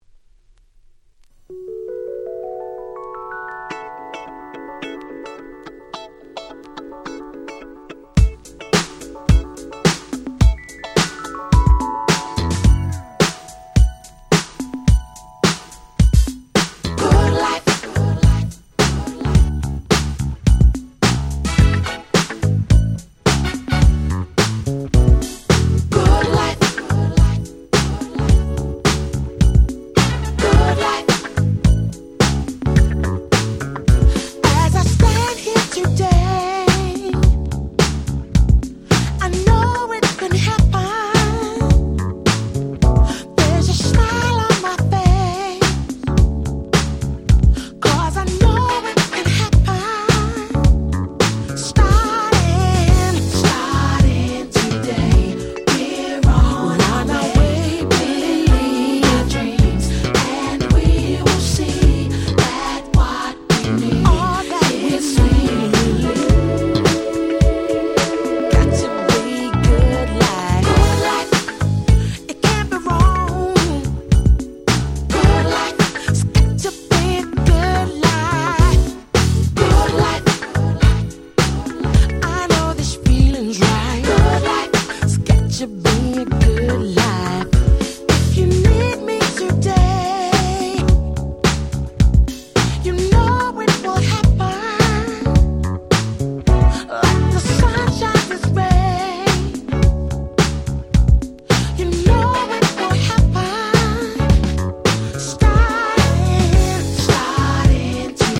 Nice UK R&B EP !!
Acid Jazz アシッドジャズ